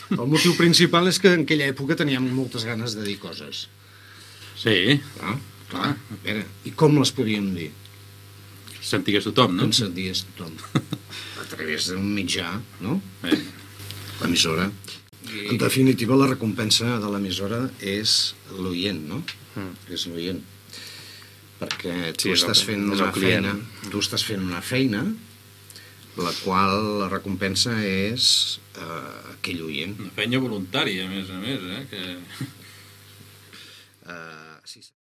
1b0f261c398f60e9efc448cee30f197bd5b095b4.mp3 Títol Ràdio El Morell Emissora Ràdio El Morell Titularitat Pública municipal Descripció Antics directors de Ràdio El Morell parlen de l'emissora en el seu 30 aniversari.